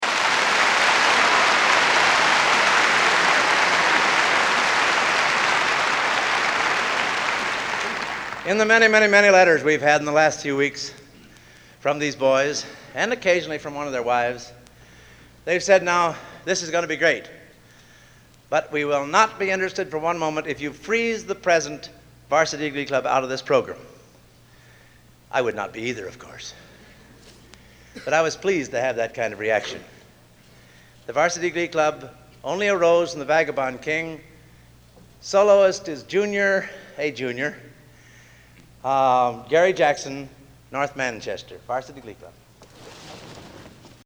Collection: Gala Anniversary Concert 1958
Location: West Lafayette, Indiana
Genre: | Type: Director intros, emceeing